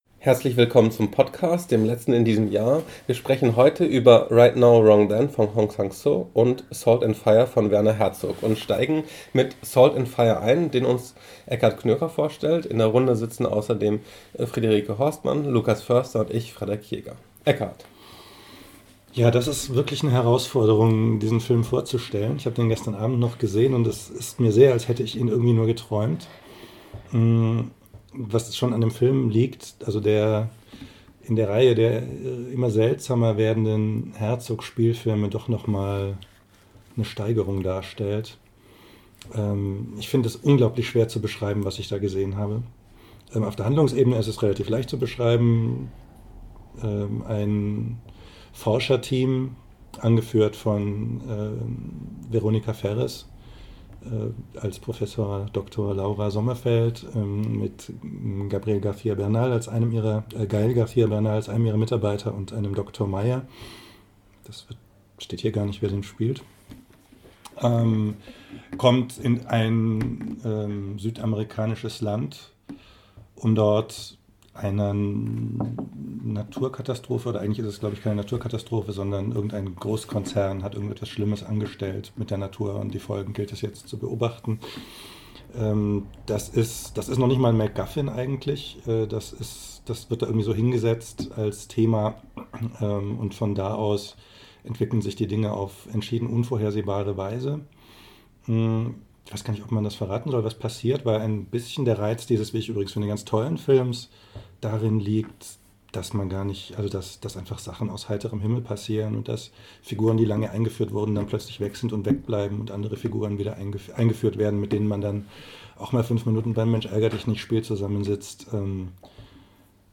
Filme, die man gar nicht so leicht zu fassen kriegt: Mit Right Now, Wrong Then kommt endlich ein Film des südkoreanischen Meisters Hong Sang-soo in deutsche Kinos, und mit Salt and Fire sorgt Werner Herzog für jede Menge Verwirrung. Ein Gespräch über zwei besondere Kinoereignisse.